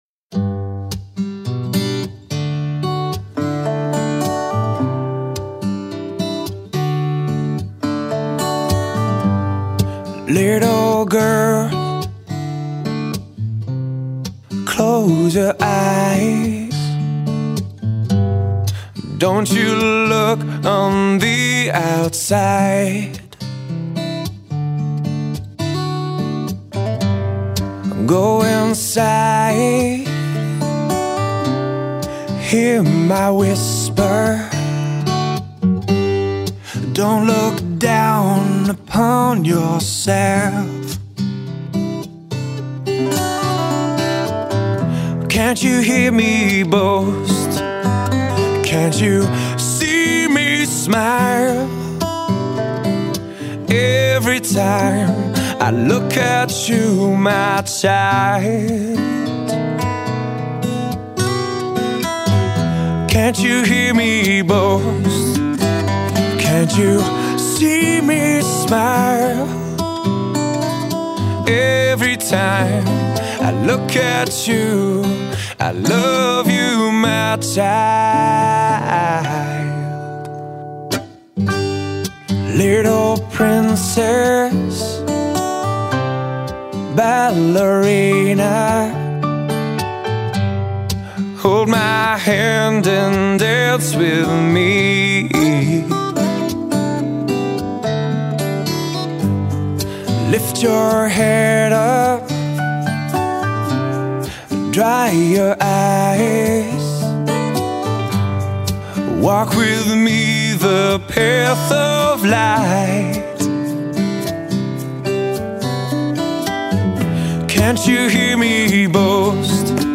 Stilrichtungen von Pop und Rock über Reggae bis zu Folk